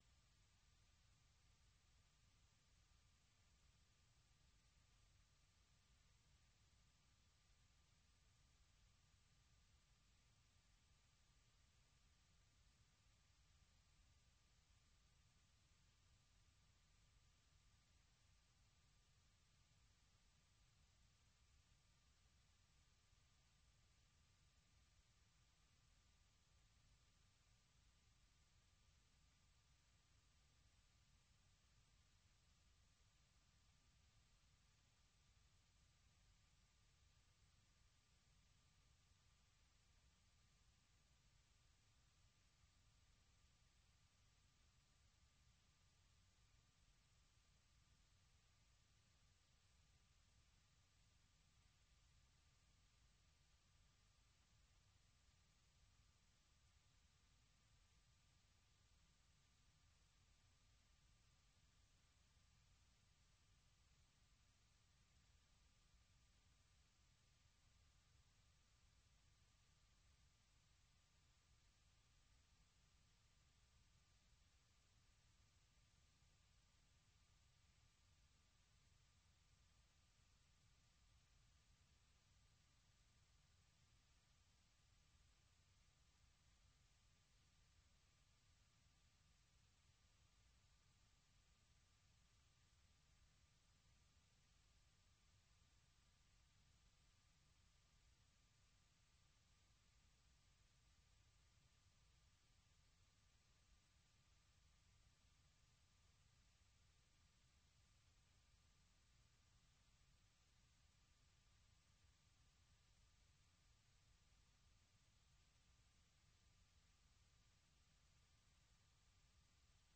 Allocution du président Donald Trump devant la session conjointe du Congrès
Vous suivez sur VOA Afrique l'édition spéciale sur l’allocution du président américain Donald Trump devant une session conjointe du Congrès, suivi de la réponse du Parti démocrate.